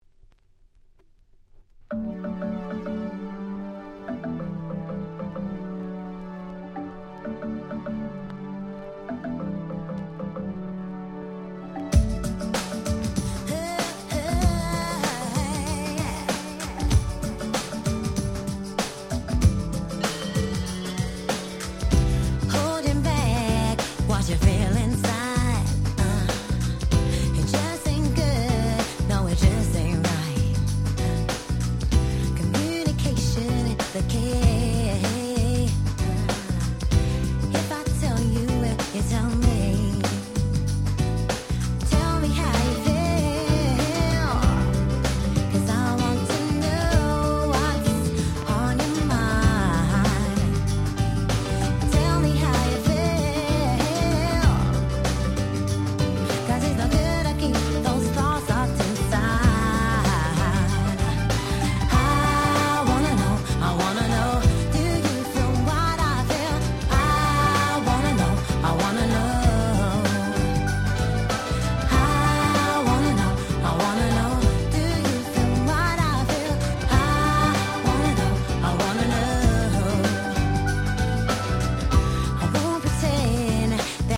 【Media】Vinyl LP